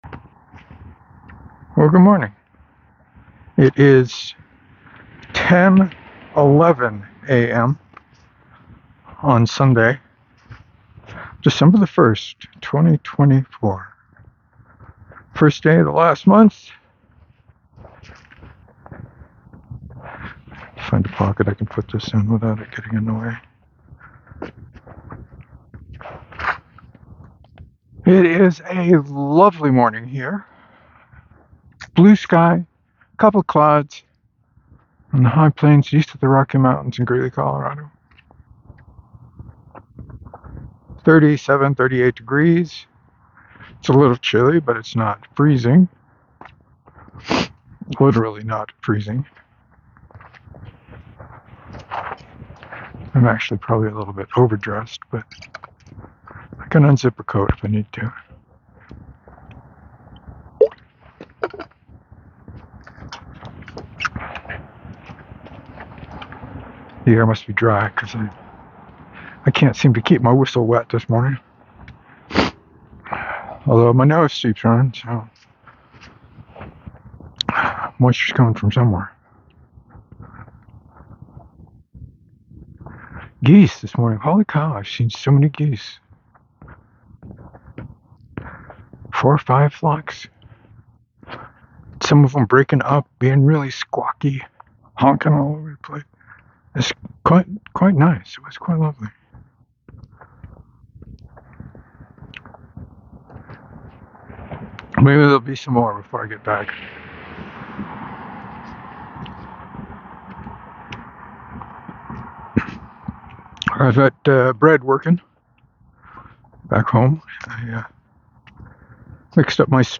Talked about the usual suspects today. Now with added geese.